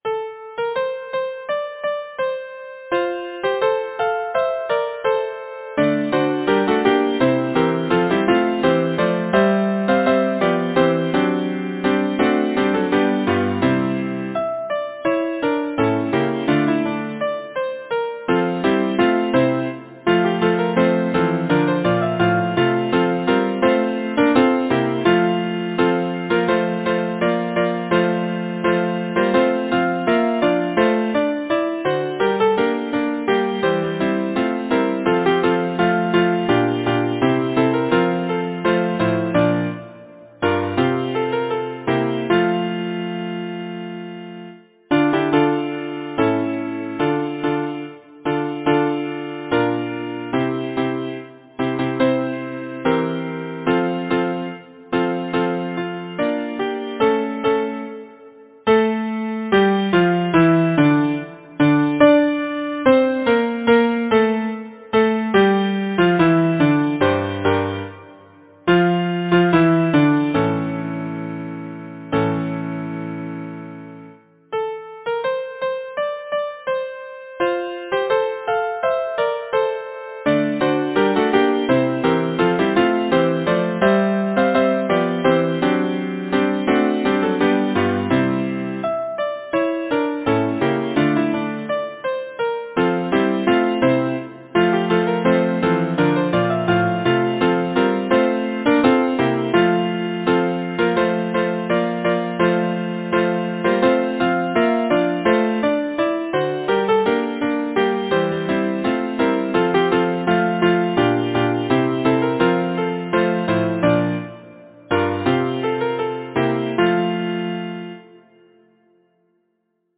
Number of voices: 4vv Voicing: SATB Genre: Secular, Partsong
Language: English Instruments: Piano